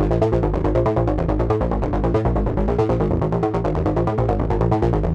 Index of /musicradar/dystopian-drone-samples/Droney Arps/140bpm
DD_DroneyArp4_140-E.wav